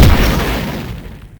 explosion_mono_01.ogg